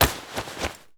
foley_combat_fight_grab_throw_06.wav